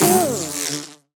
sounds / mob / bee / death1.ogg